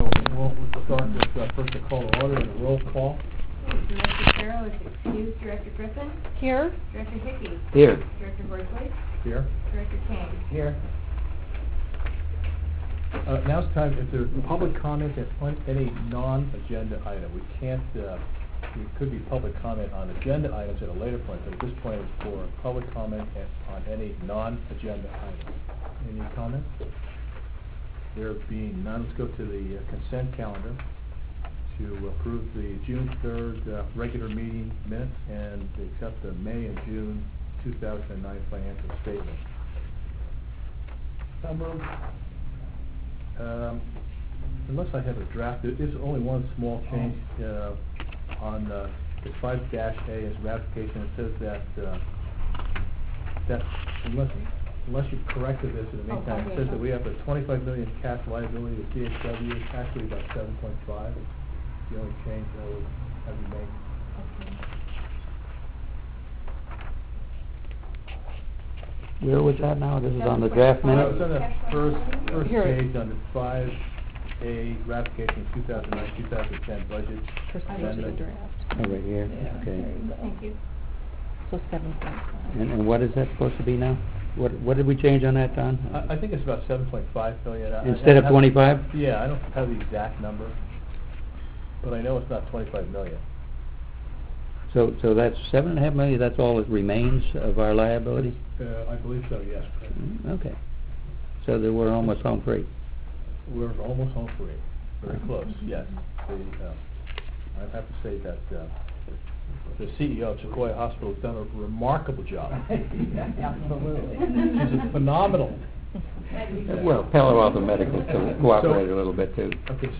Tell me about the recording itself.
My effort to get the audio recordings of Board meetings up on our website is a work in progress.